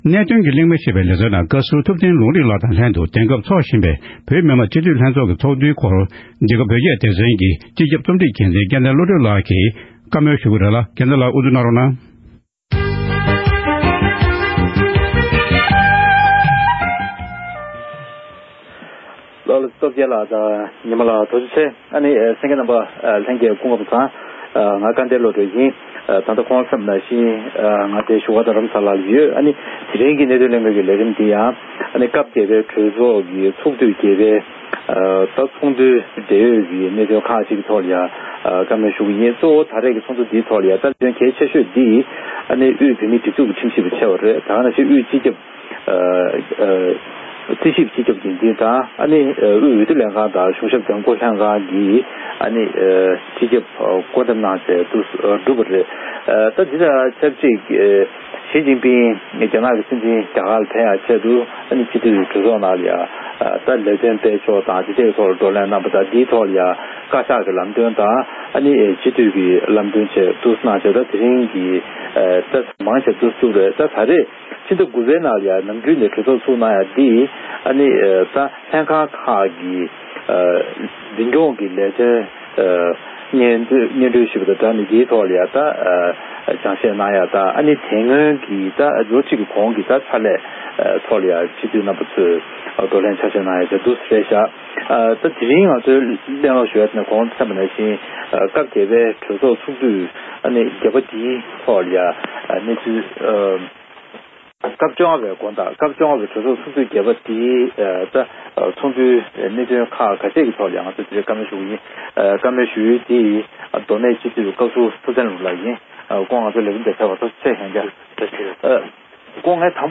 སྤྱི་འཐུས་ཐུབ་བསྟན་ལུངས་རིགས་ལགས་རླུང་འཕྲིན་ཁང་གི་སྒྲ་འཇུག་ཁང་དུ་གླེང་མོལ་གནང་བཞིན་པ།